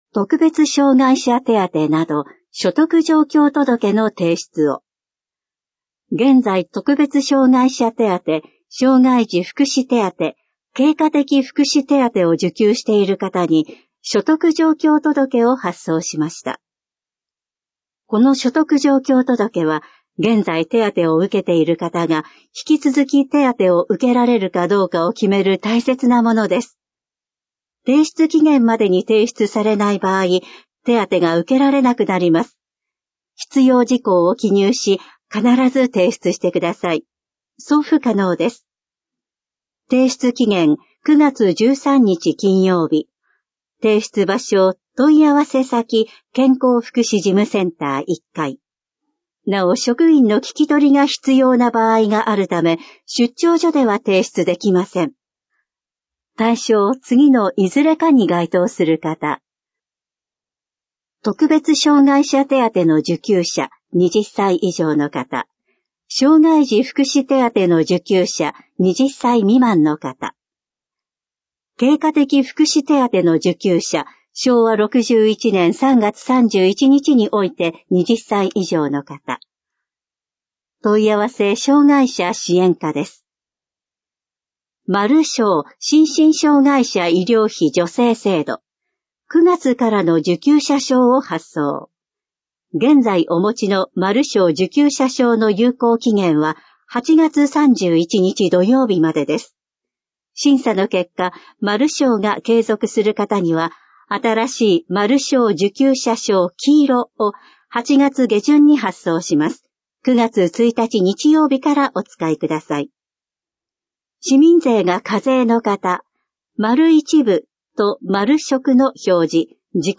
市報音声版「声のたより」市報こだいら2024年8月20日号音声版｜東京都小平市公式ホームページ